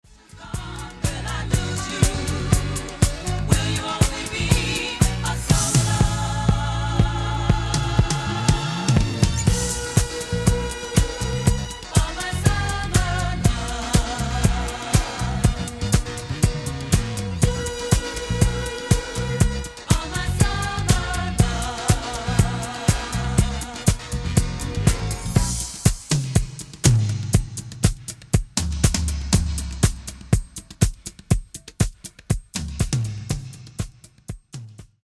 Genere:   Disco | Funk
Registrato al Blank Tape Studios New York